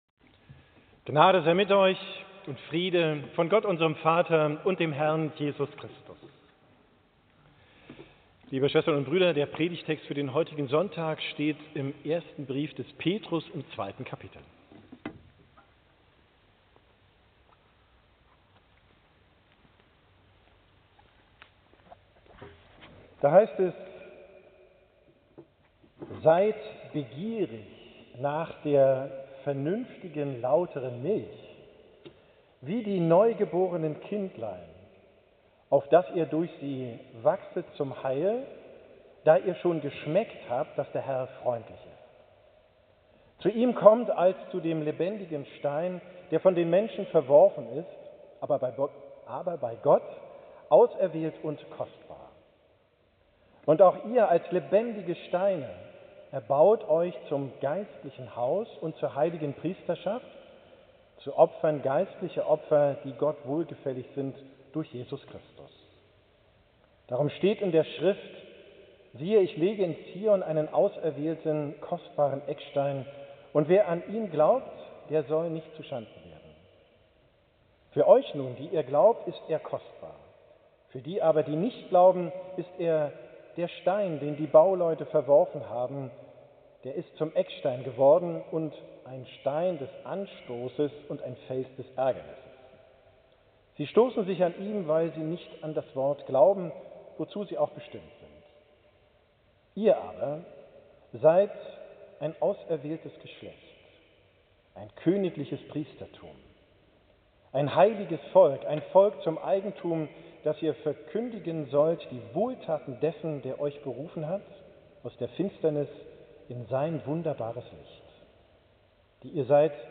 Predigt vom 6. Sonntag nach Trinitatis, 27.